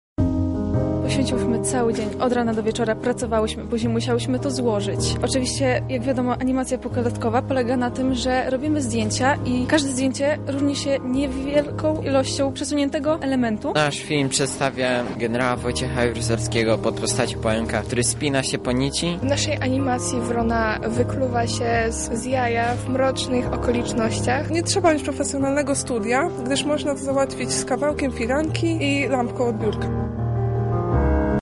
Na miejscu obecny był nasz reporter: